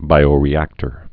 (bīō-rē-ăktər)